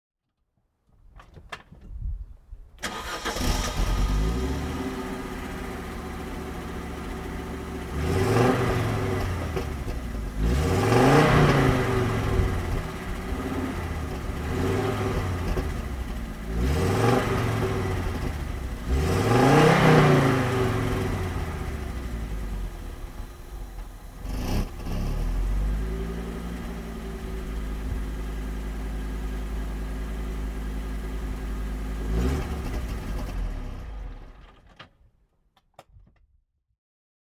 Audi 90 2.3E quattro (1988) - Starten und Leerlauf